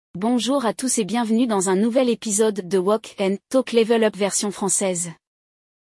Durante o episódio, você vai ouvir um diálogo real, com falantes nativos, que te ajudará a aprimorar tanto a escuta quanto a pronúncia.
Você ouve um diálogo em francês, acompanha a explicação do vocabulário e repete em voz alta para treinar a pronúncia.